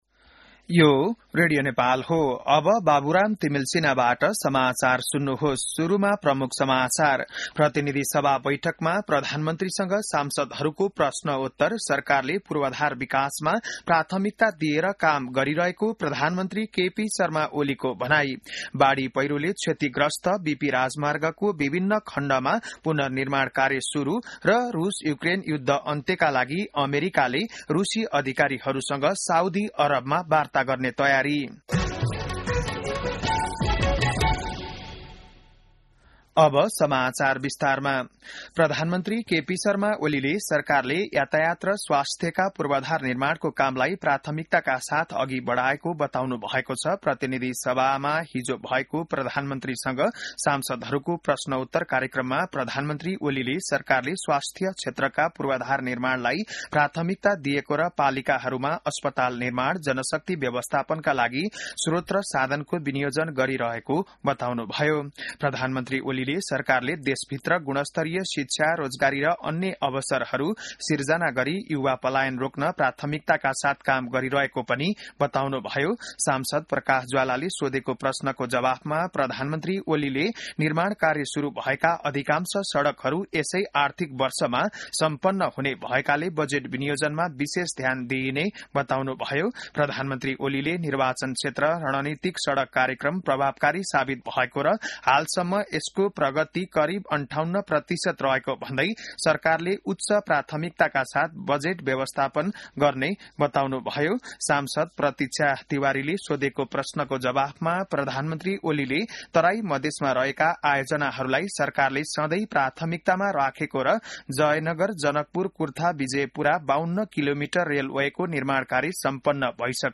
बिहान ९ बजेको नेपाली समाचार : ६ फागुन , २०८१